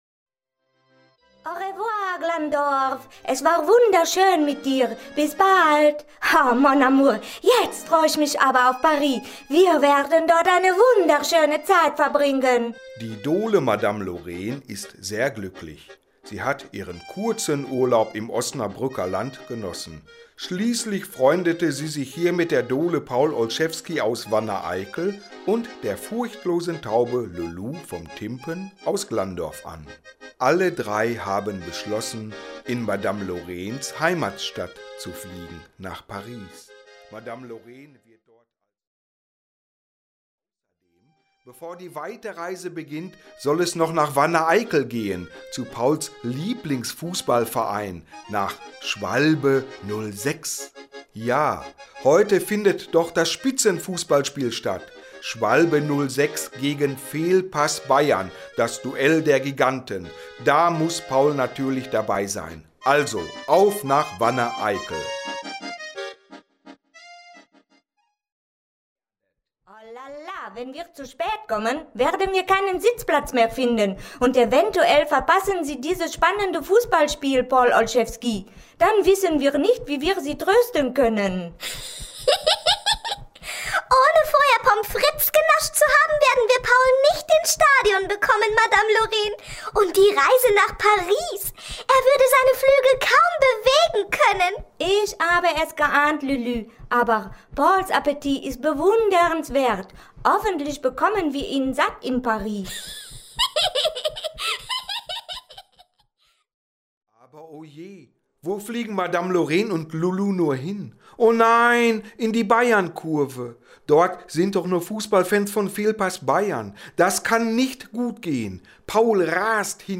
Ein Hörbuch mit Musik